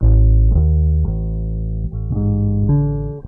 BASS 3.wav